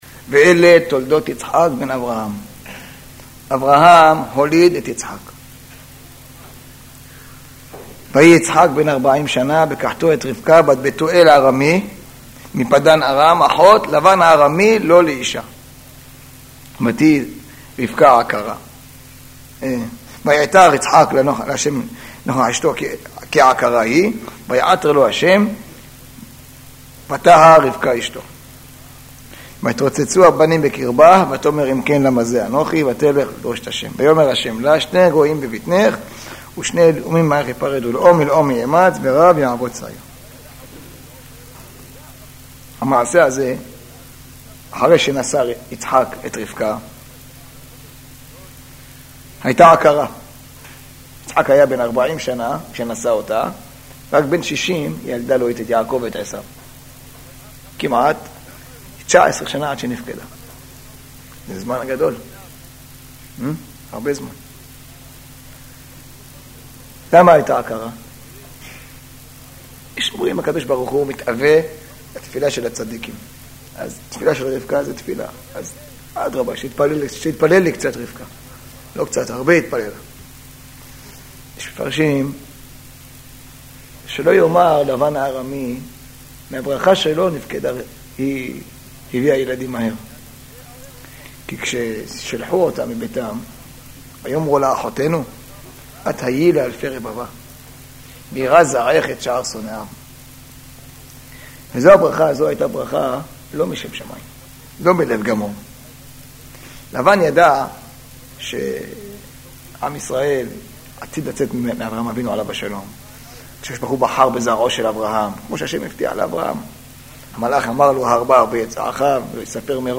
שיעורי שמע